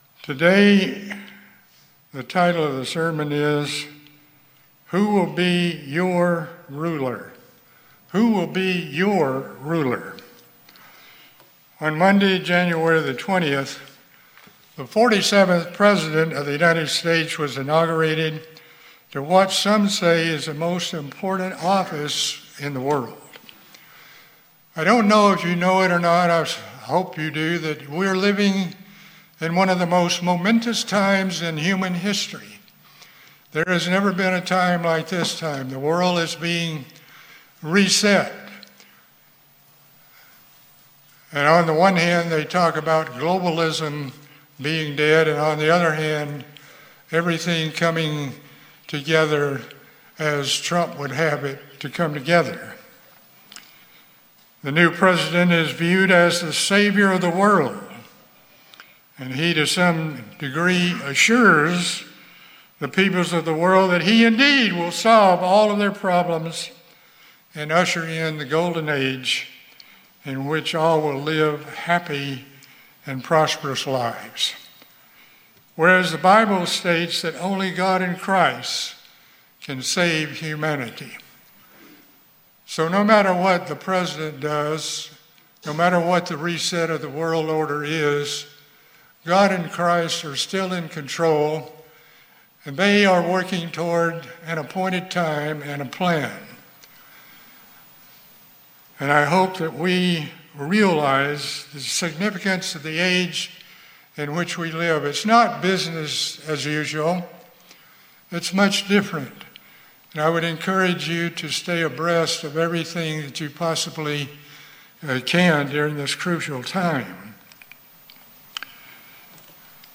This sermon explore current trends in rulership which parallels the end time Beast system.